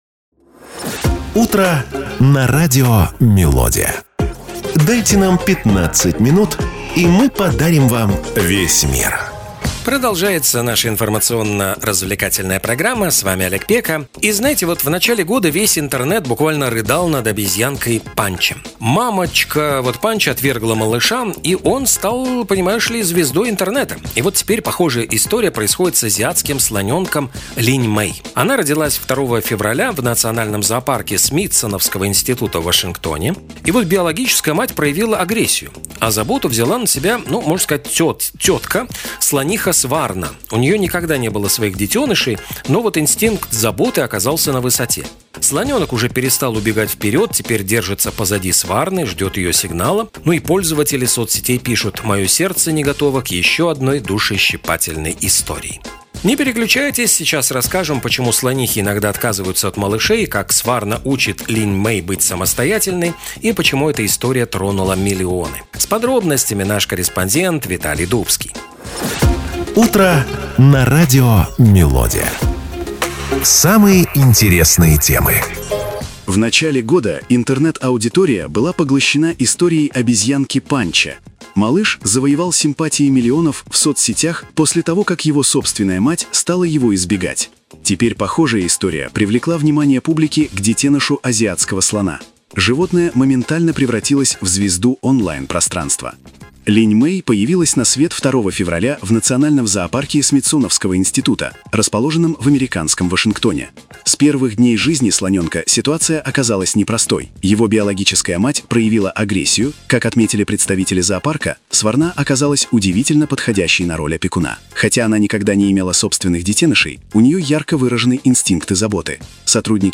Разговоры о политике, спорте, искусстве и многом другом